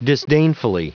Prononciation du mot disdainfully en anglais (fichier audio)
Prononciation du mot : disdainfully